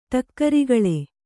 ♪ ṭakkarigaḷe